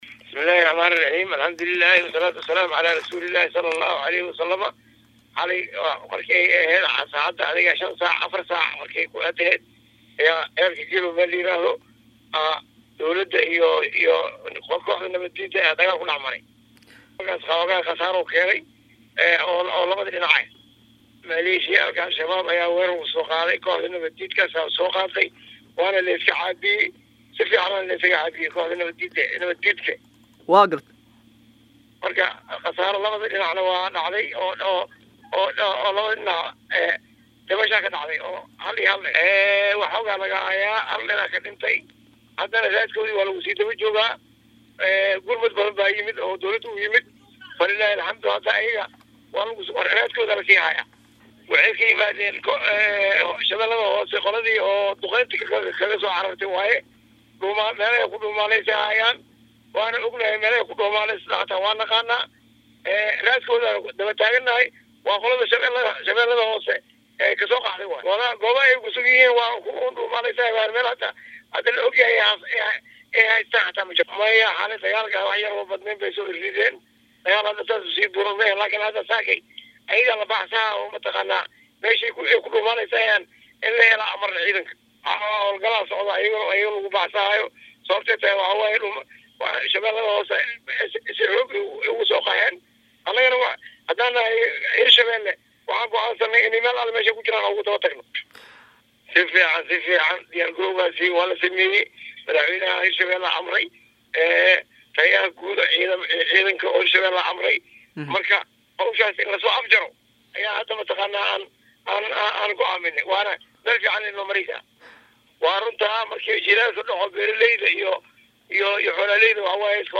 wareysi uu siyay Radio Muqdisha Guddoomiye ku xigeenka Amniga iyo siyaasada Maamulka Hirshabelle Abuular Aadan Caleey Balcad ayaa sheegay in ay ku sii daba jiraan firxadka Argagixiisada Al-Shabaab ee ka caraaray hawlgalada ciidaanka Xogga Dalka.